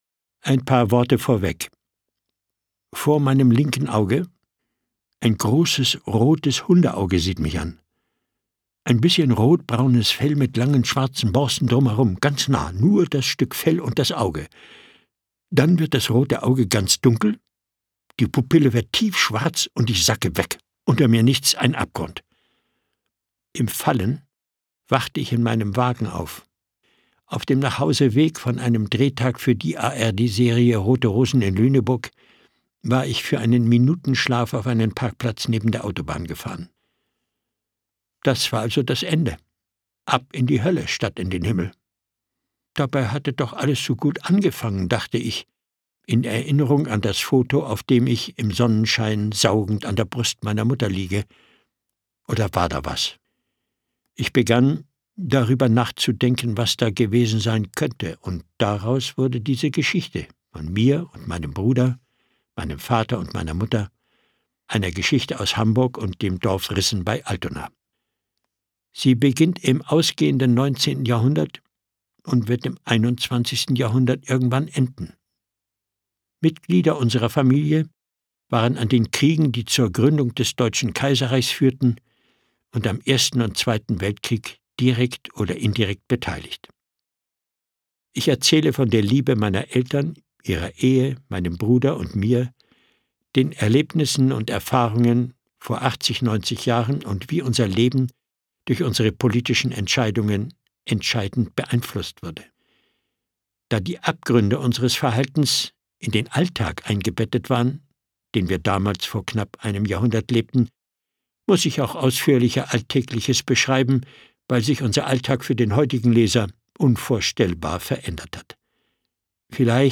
2020 | Autorenlesung ; Autorisierte Audiofassung